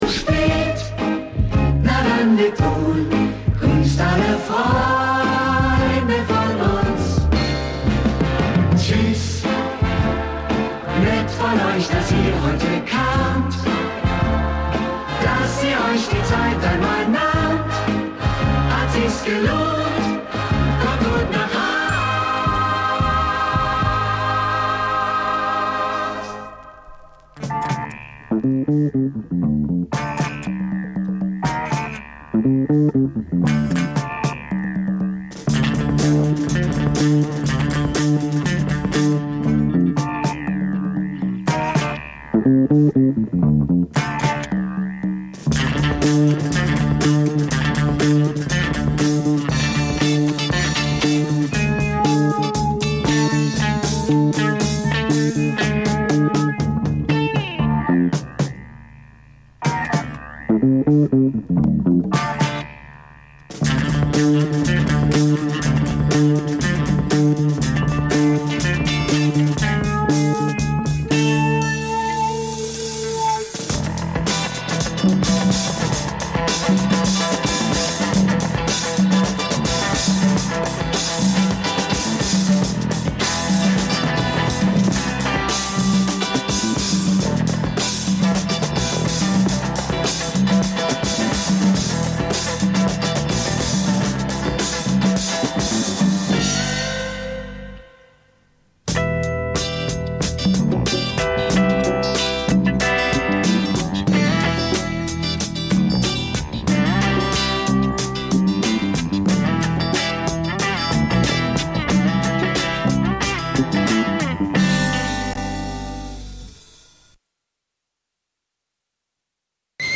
Hinter WHAT ABOUT BREAKS? verbergen sich unter dem alles �berspannenden Dach der Hip Hop Kultur neben den vorher genannten Stilrichtungen auch BLUES, LATIN, ELECTRO, REGGAE und POP mit jeder Menge Hintergrundinfos zur Musik und den K�nstlern.